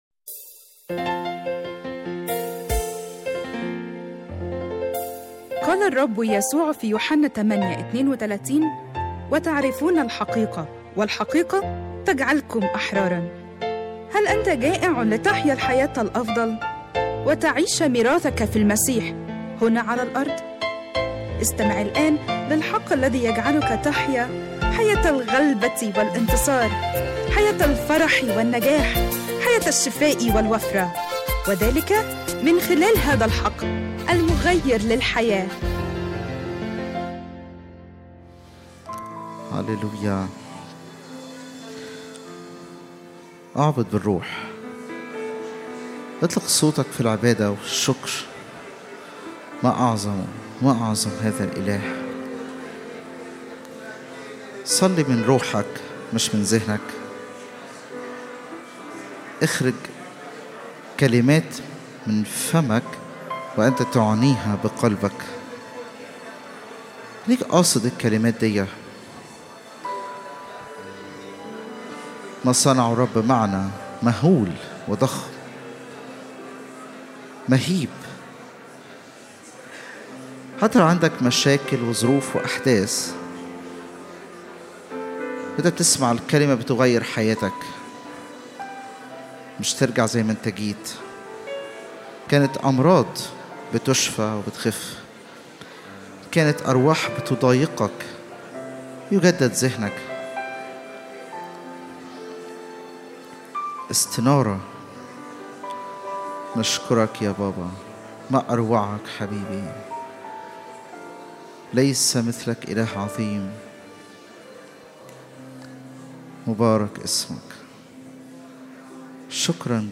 🎧 Download Audio 1 تحميل اجتماع الثلاثاء 10/3/2026 لسماع العظة على الساوند كلاود أضغط هنا لمشاهدة العظة على اليوتيوب من تأليف وإعداد وجمع خدمة الحق المغير للحياة وجميع الحقوق محفوظة.